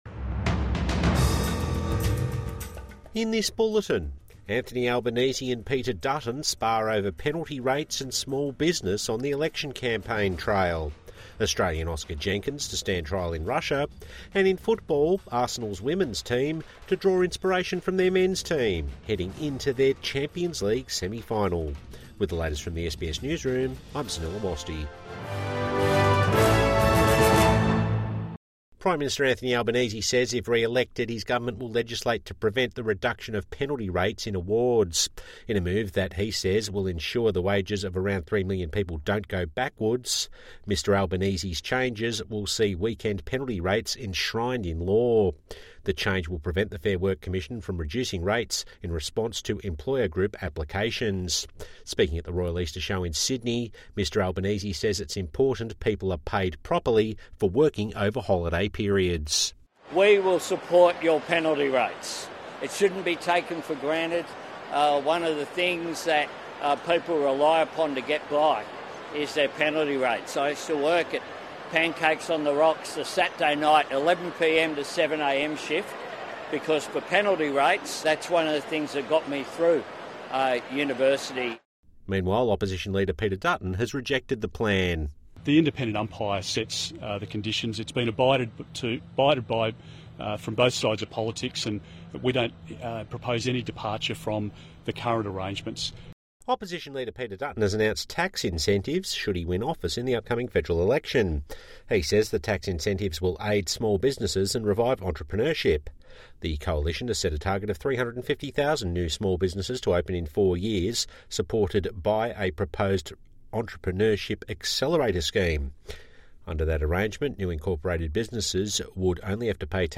A bulletin of the day’s top stories from SBS News.